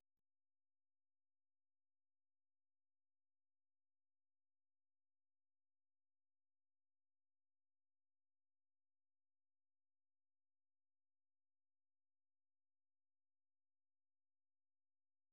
Другая полька, напеваемая Хоботовым «старая французская песенка» C'est Gugusse avec son violon («Мон папа́ не вё па…»), была переведена Костиком как «Мой отец запрещал, чтоб я польку танцевала…».